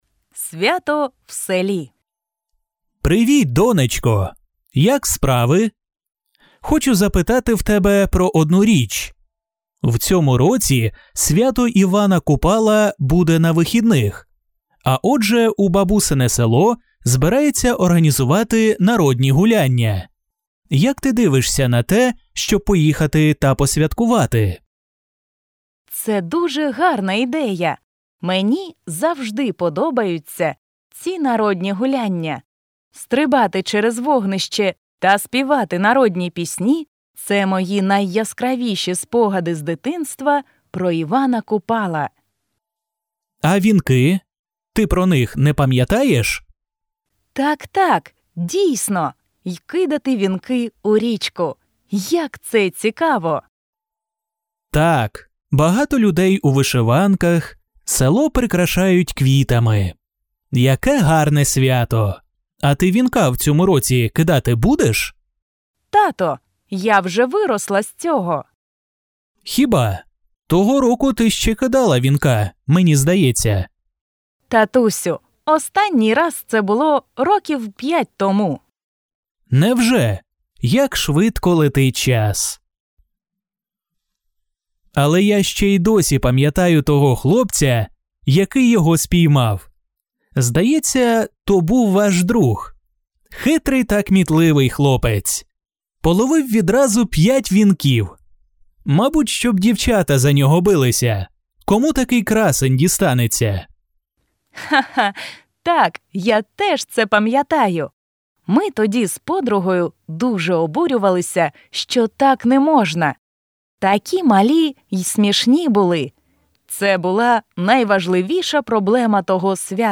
Dialogues with audio
dialogue-advanced-village-holiday.mp3